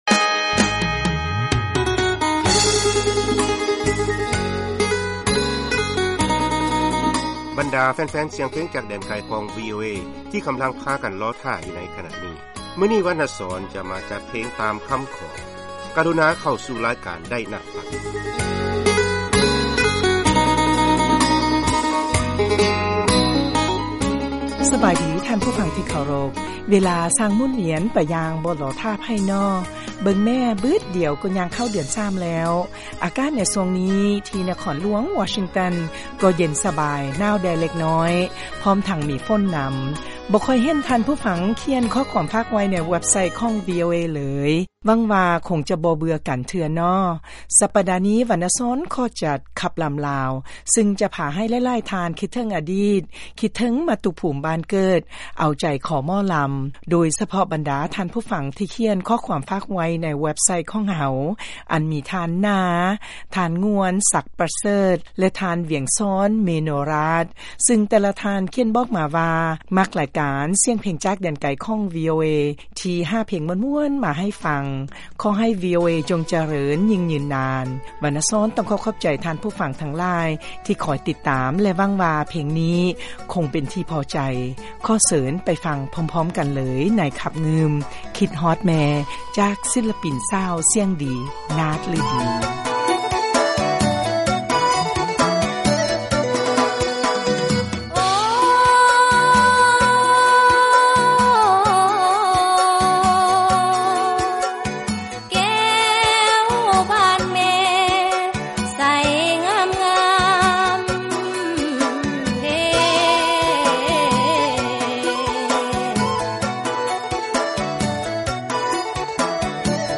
ເປັນຂັບງື່ມ